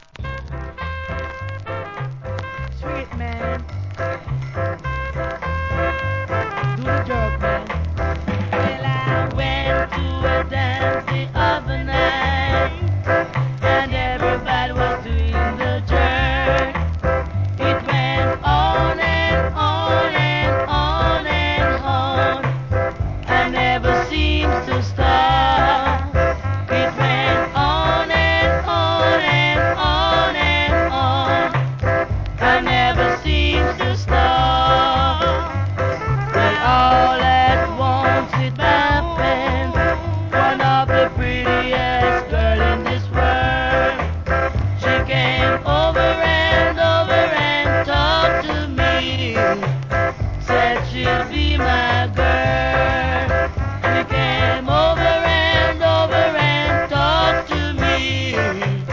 終盤で周期的なノイズ、SAMPLE確認ください。最後の最後で針飛びします。)
REGGAE